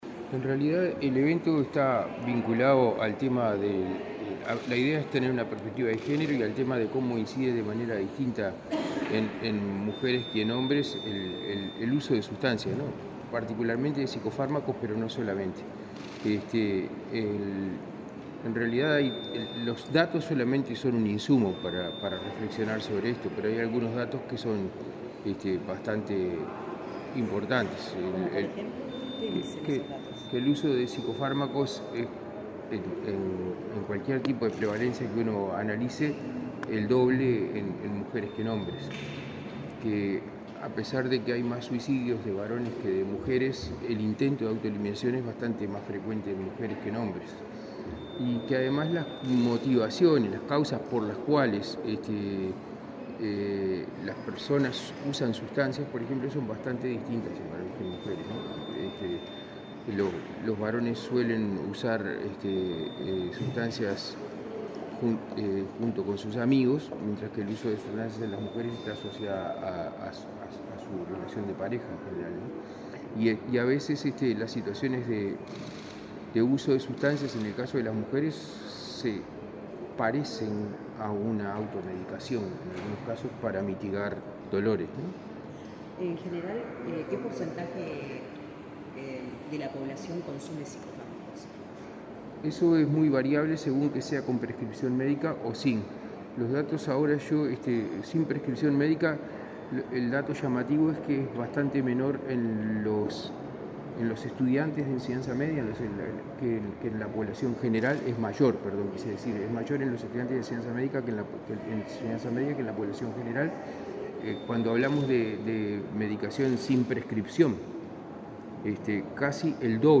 Declaraciones a la prensa del titular de la Secretaría de Drogas, Daniel Radío